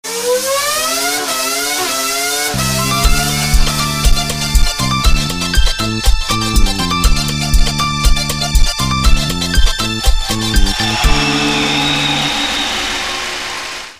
ferrari-sound_24859.mp3